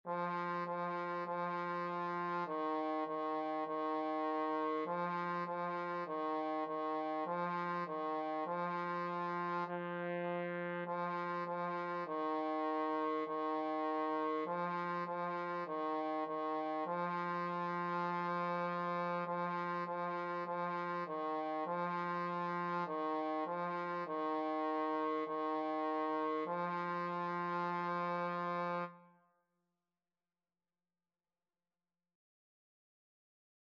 Eb4-F4
4/4 (View more 4/4 Music)
Trombone  (View more Beginners Trombone Music)
Classical (View more Classical Trombone Music)